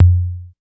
Tom Fiasco 4.wav